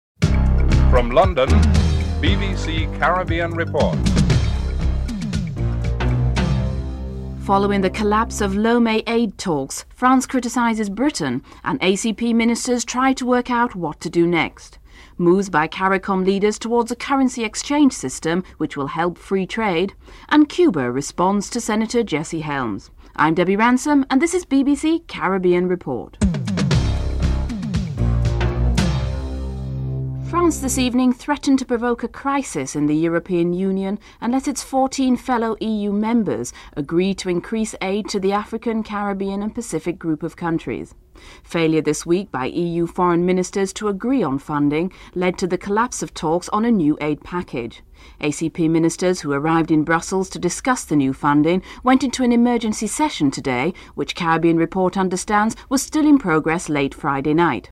Cuba's Foreign Minister Rafael Dausa responds to Senator Jesse Helms proposals to tighten the US embargo on Cuba.
6. Report on the growing interests in sending aid to Cuba (12:29-14:36)